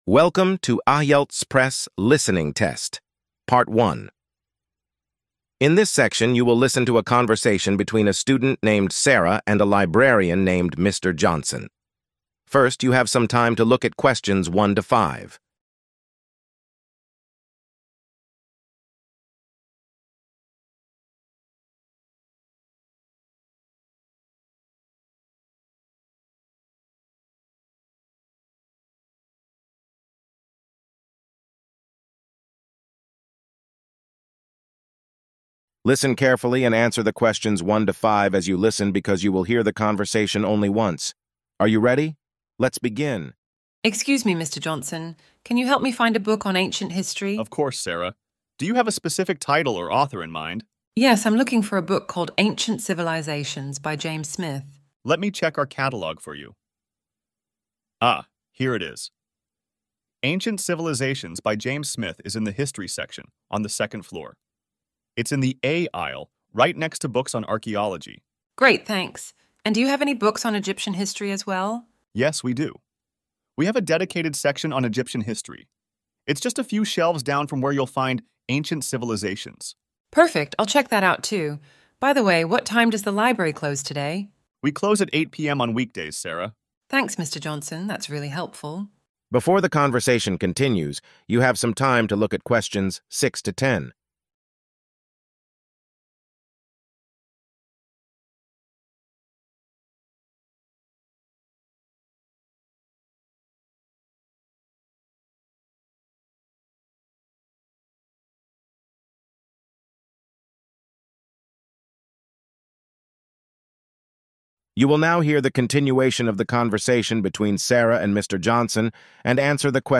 IELTS LISTENING FULL PRACTICE TEST - IELTS PRESS